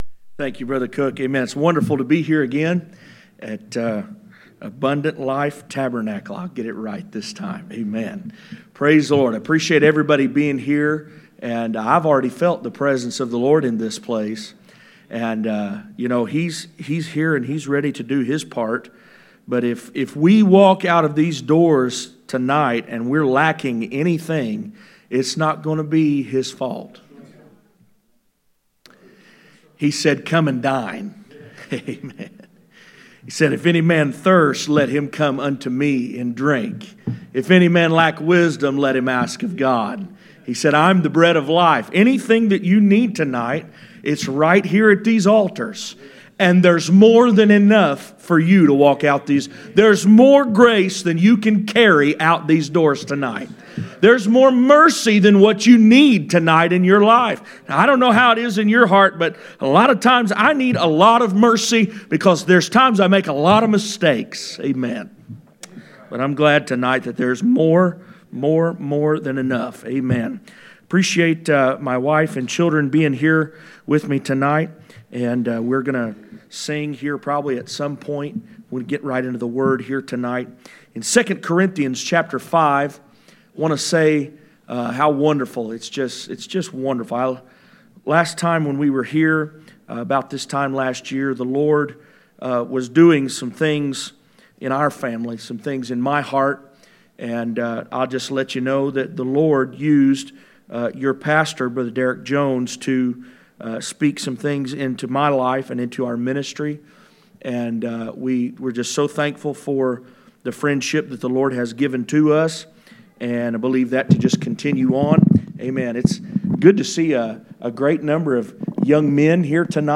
2 Corinthians 5:17 Service Type: Special event %todo_render% « The Precious priority of the Lord’s Day.